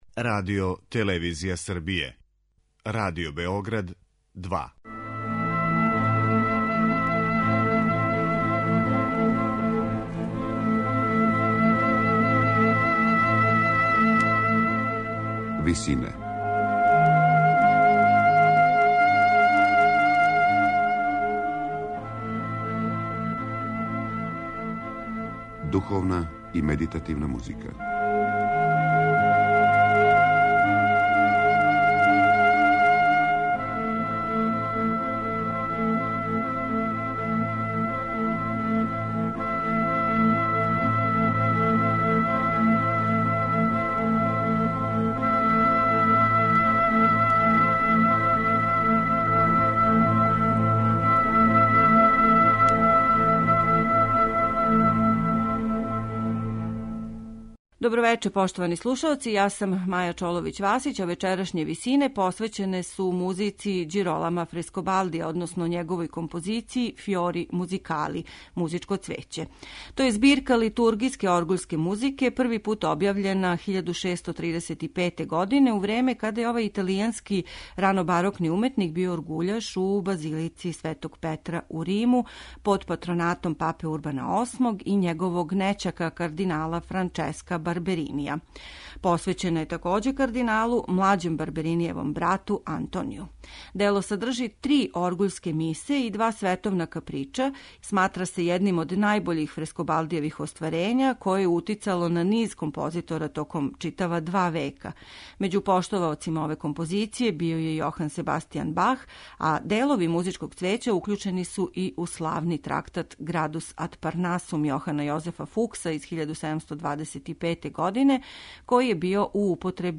Дело које чине три оргуљске мисе овог ранобарокног италијанског композитора, имало је утицаја на низ композитора током готово два века након објављивања 1635. године.